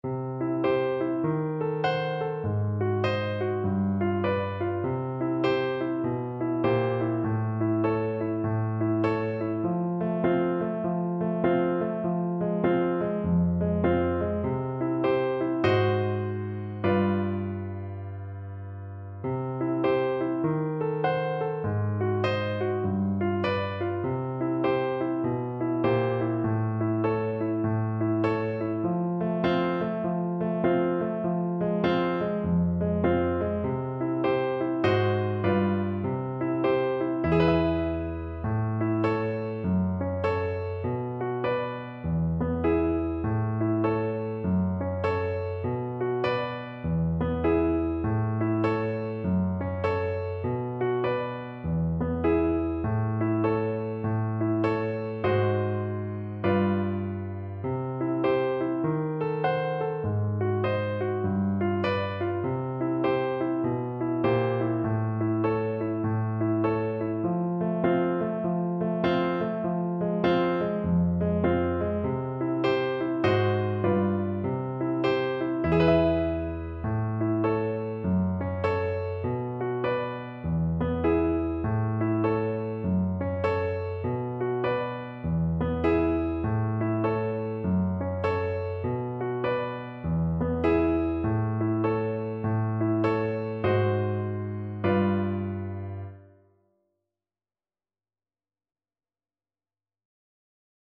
Piano version
4/4 (View more 4/4 Music)
Piano  (View more Easy Piano Music)
Pop (View more Pop Piano Music)